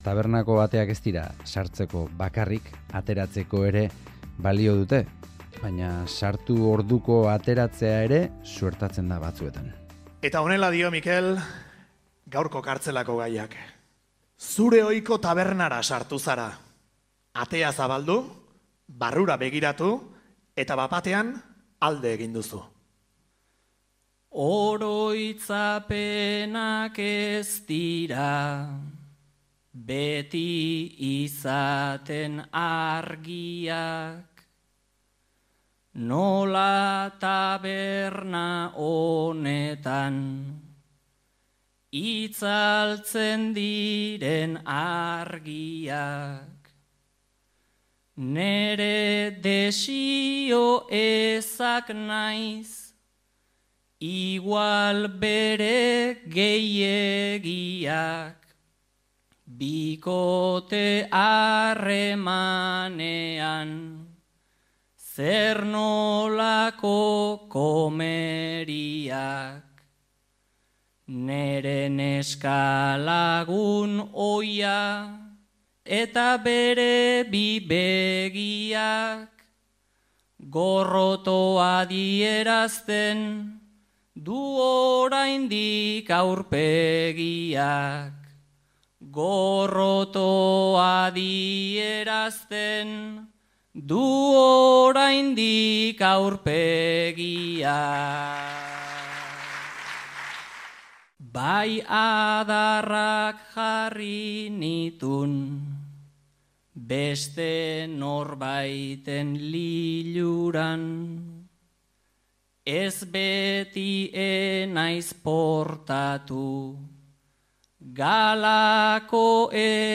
Gipuzkoako Bertsolari Txapelketan Lasarte-Orian egindako kartzelako lana